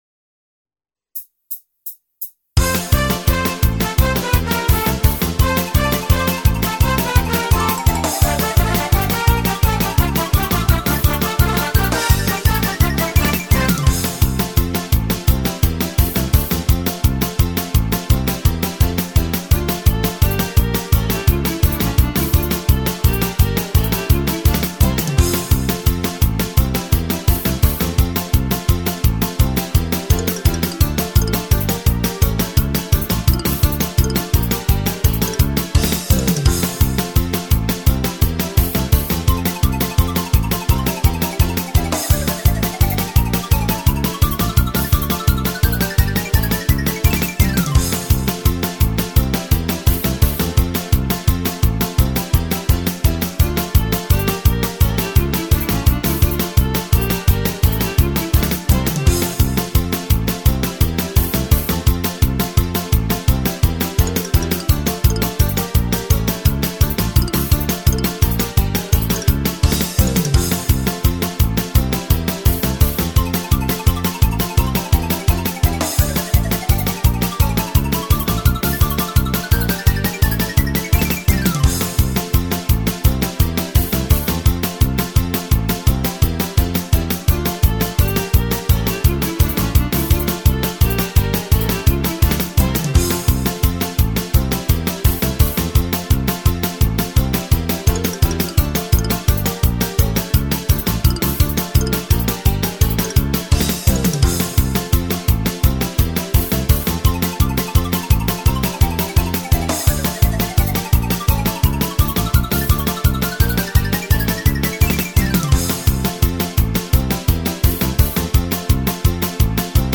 Фонограма-мінус (mp3, 192 kbps). весільна полька